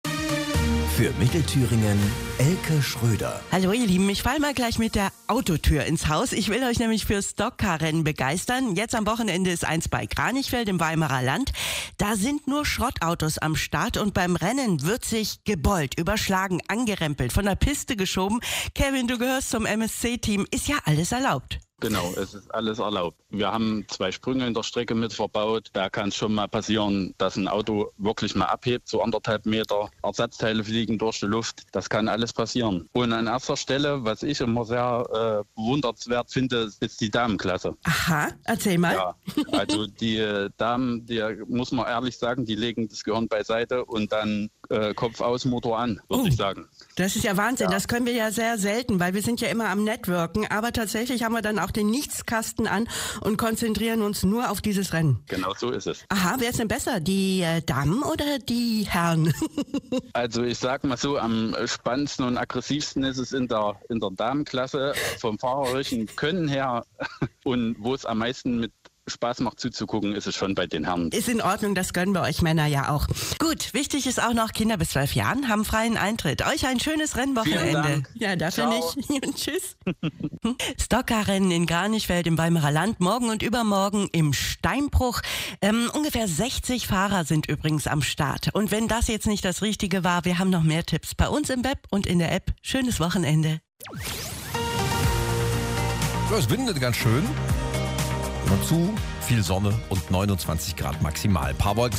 Radio-Stockcar.mp3